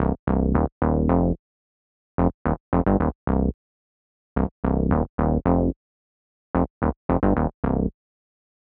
30 Bass PT2.wav